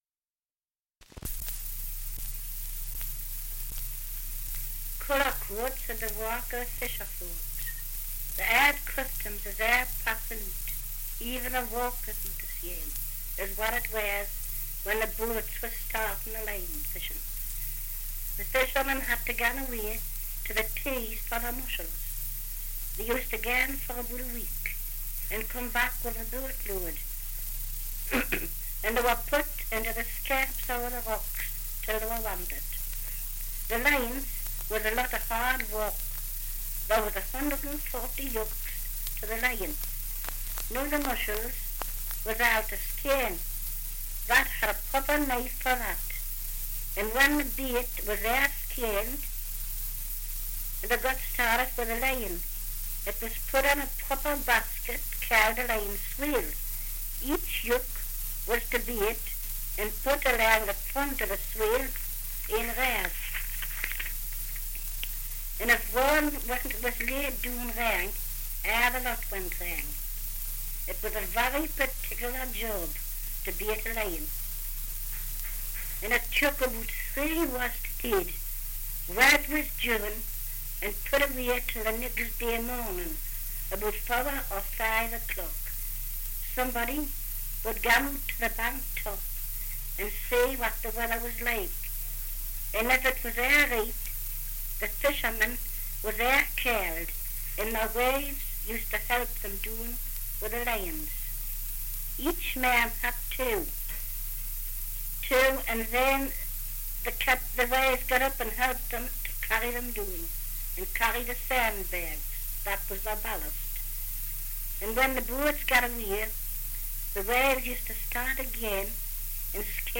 Dialect recording in Cullercoats, Northumberland
78 r.p.m., cellulose nitrate on aluminium